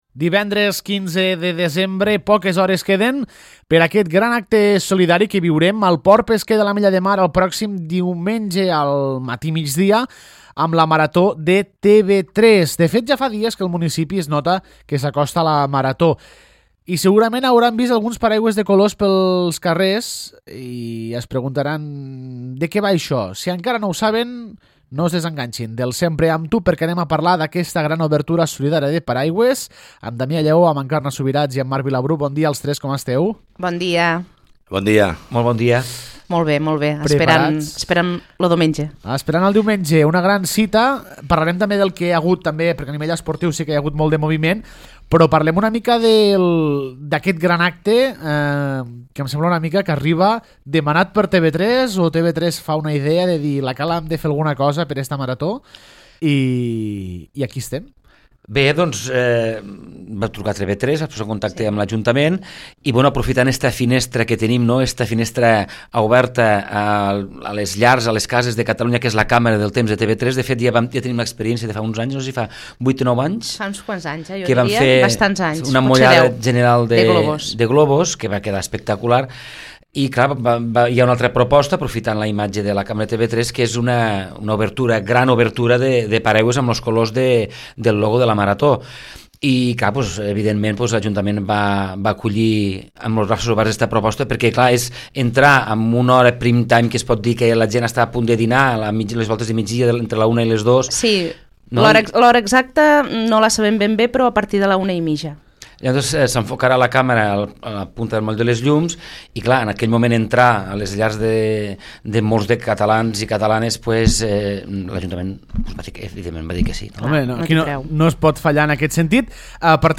L’entrevista: Gran Obertura de Paraigües per La Marató